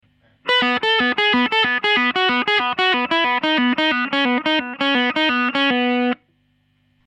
In this song Angus Young uses the open B string to pull off notes to create arpeggios followed by a pattern that runs down the B mixolydian mode. Here is an example in a similar style.
Open String Lick Thunderstruck Style